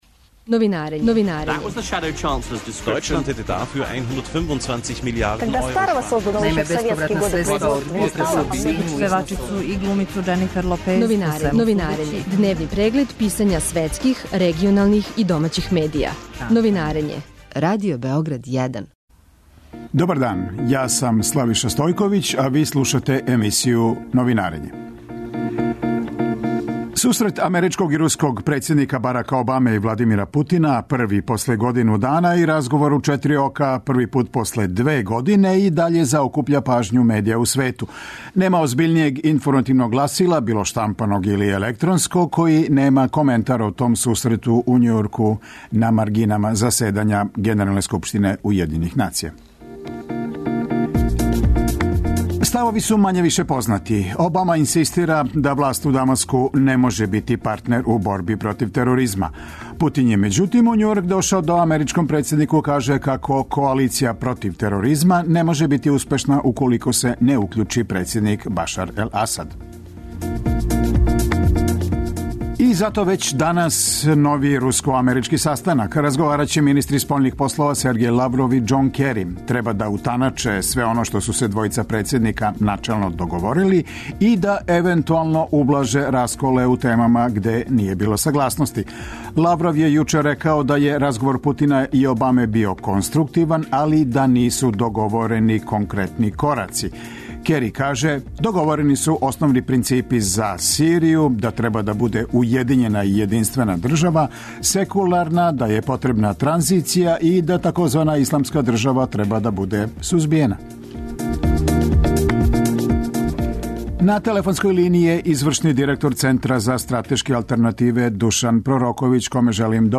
Новинарење | Радио Београд 1 | РТС